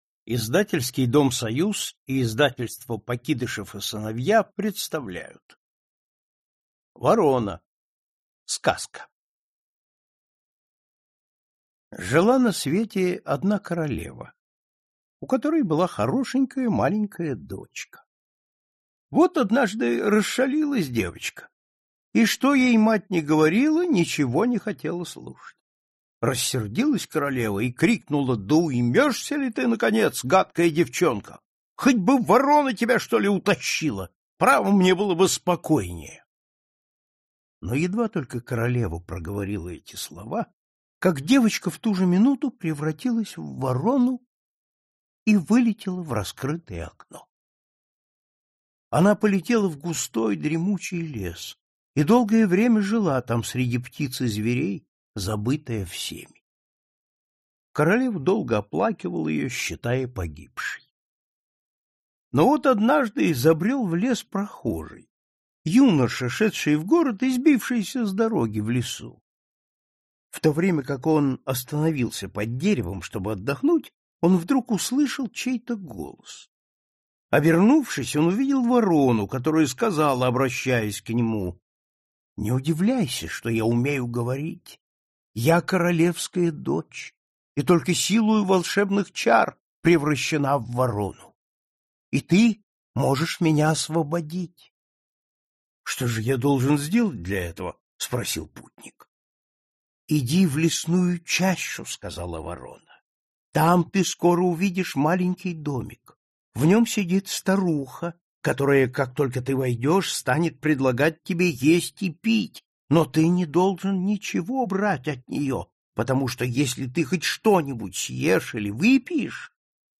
Аудиокнига Принц Щелкун, Царевич-лягушка и другие сказки | Библиотека аудиокниг